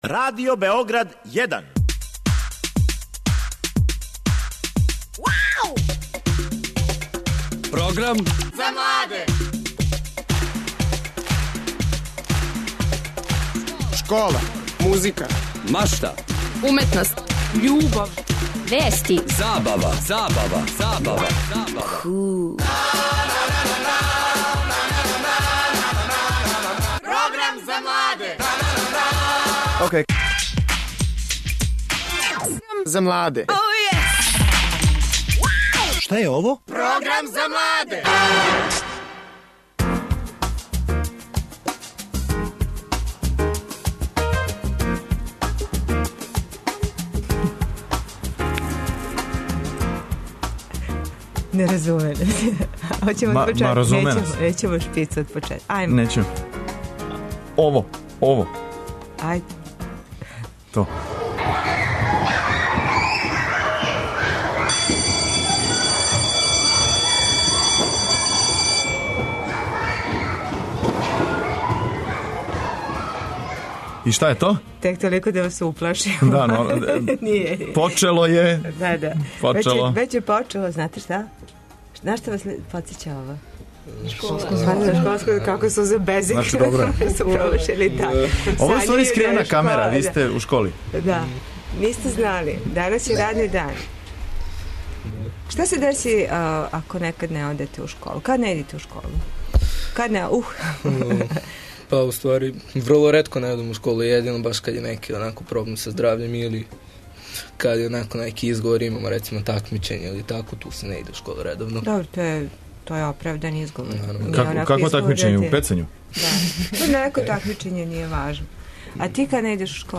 У студију ће бити и одрасли, а свакако и тинејџери.